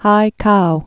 (hīkou, -kō)